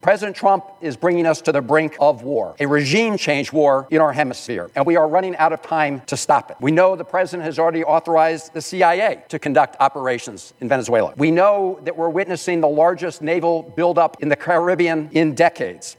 U.S. Senator Chris Van Hollen took to the Senate Floor urging passage of the Prohibiting Unauthorized Military Action in Venezuela Act, legislation he cosponsored to prohibit the use of federal funds for any military force against Venezuela without explicit Congressional authorization…